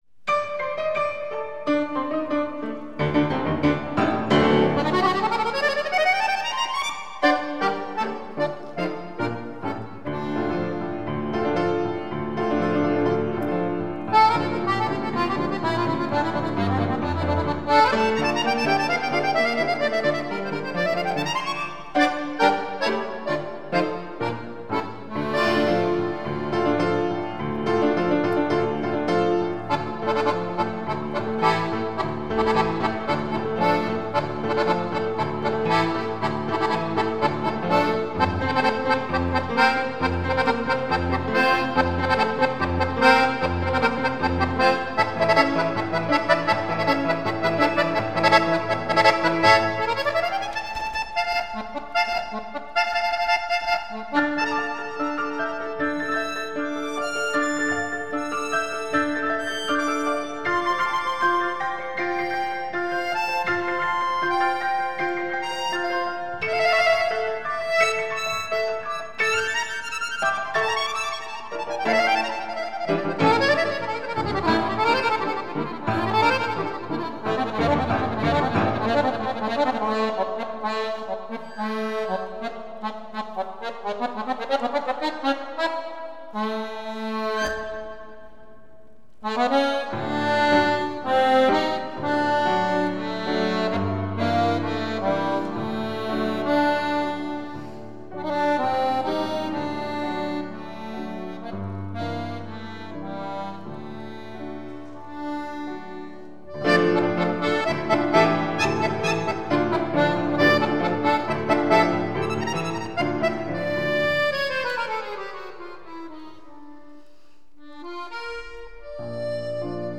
Fisarmonica
Pianoforte